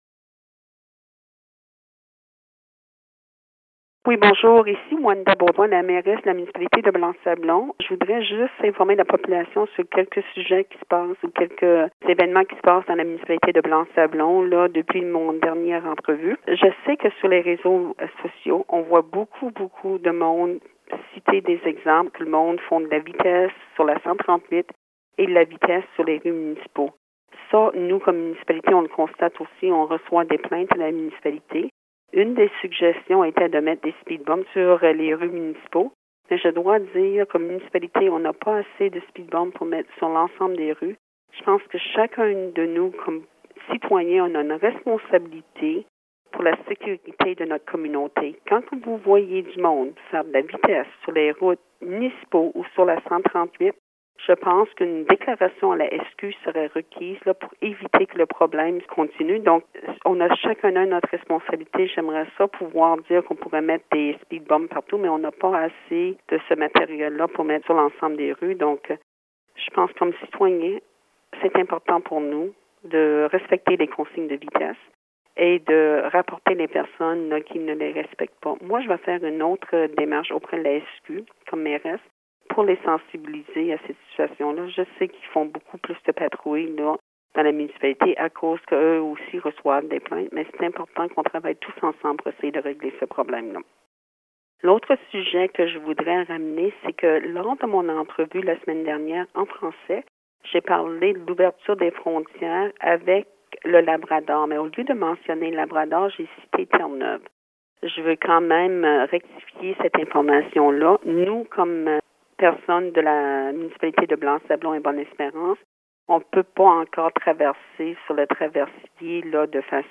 An update from the Mayor of the Municipality of Blanc-Sablon, Wanda Beaudoin, regarding: